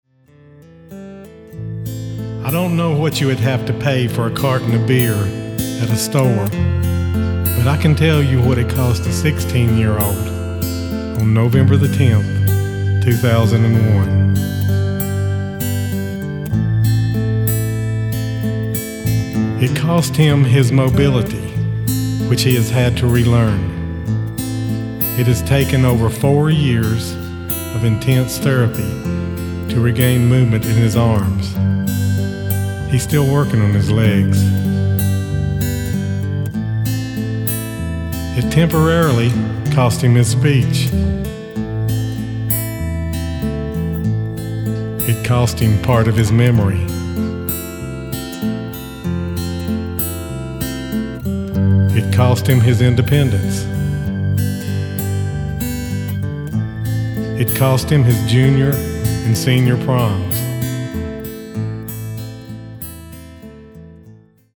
Autoharp, Lead & Harmony Vocals
Drums, Lead & Harmony Vocals
Guitar, Lead & Harmony Vocals
Keyboards, Bass Vocals
Bass, Lead & Harmony Vocals